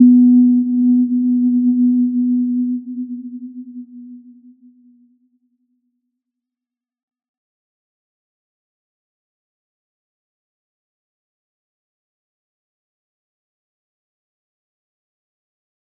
Little-Pluck-B3-p.wav